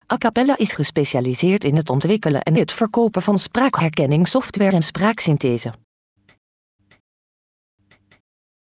Texte de d�monstration
Acapela High Quality Text To Speech Voices; distribu� sur le site de Nextup Technology; femme; hollandais